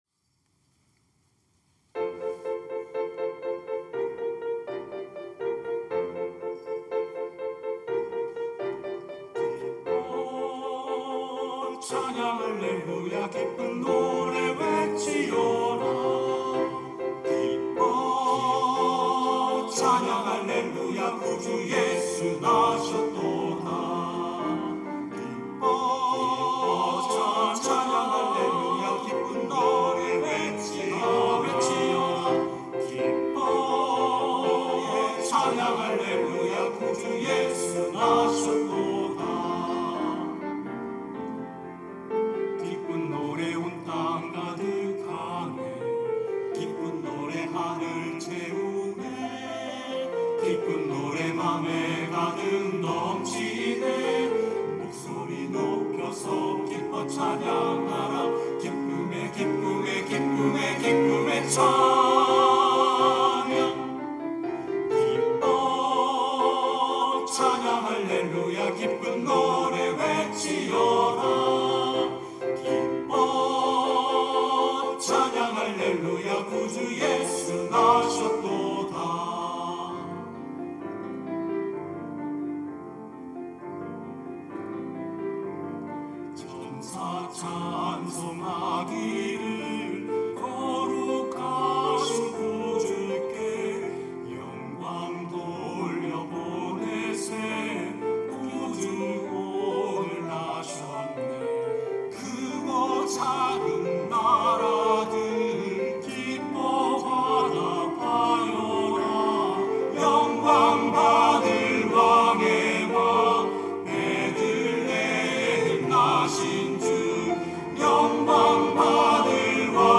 천안중앙교회
찬양대 카리스